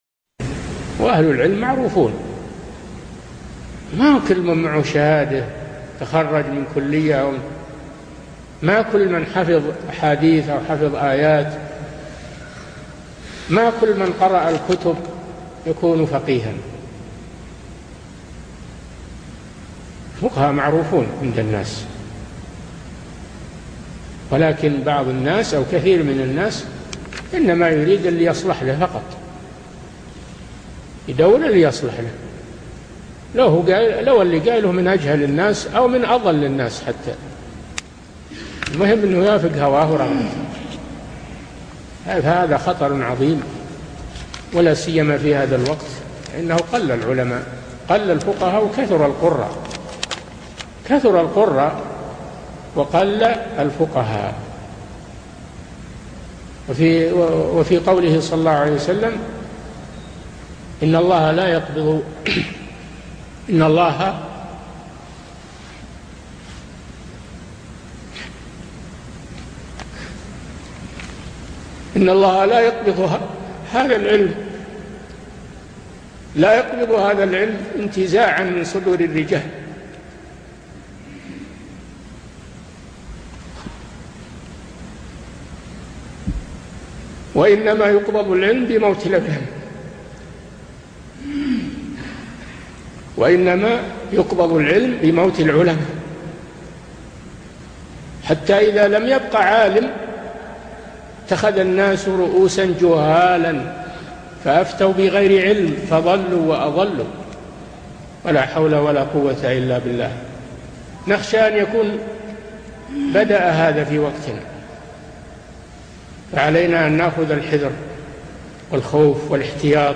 Download audio file Downloaded: 407 Played: 283 Artist: الشيخ صالح الفوزان Title: كثر القراء و قلّ الفقهاء في هذا الزمن Album: موقع النهج الواضح Length: 2:43 minutes (1.28 MB) Format: MP3 Mono 22kHz 64Kbps (CBR)